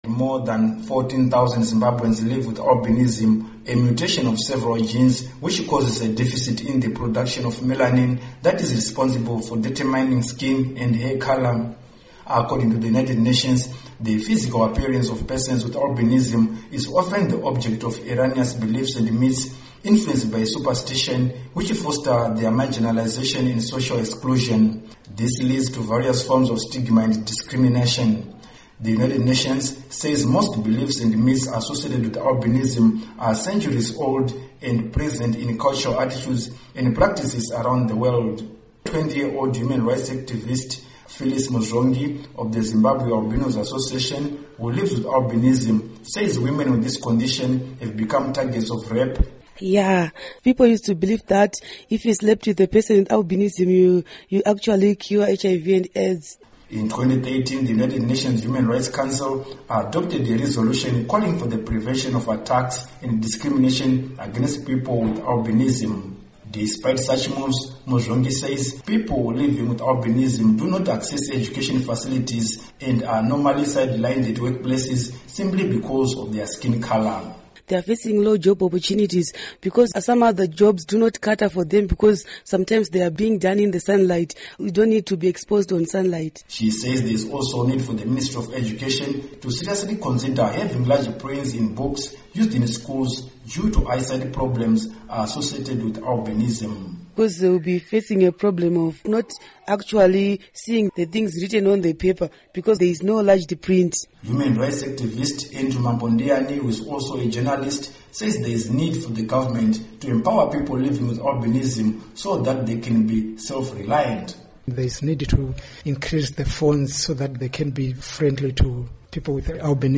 Report on Albinism